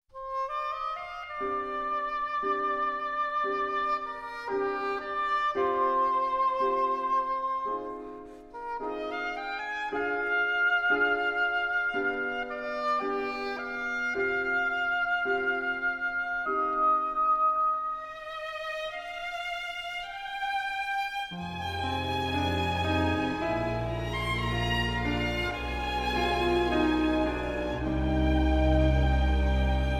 the lyrical oboe in opera and cinema
oboe, oboe d'amore